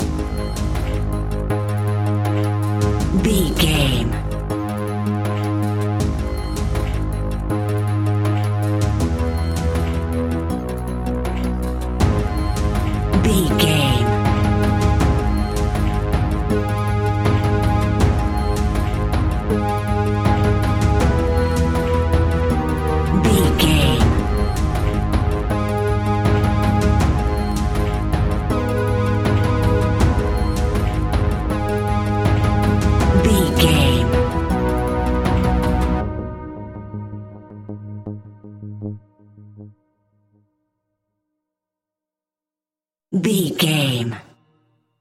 Aeolian/Minor
G#
ominous
dark
haunting
eerie
driving
synthesiser
brass
percussion
horror music